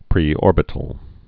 (prē-ôrbĭ-tl)